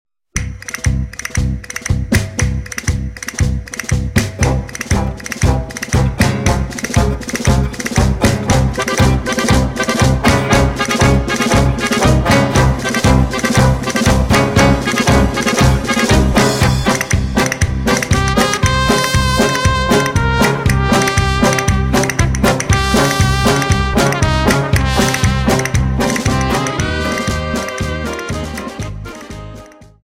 Dance: Paso Doble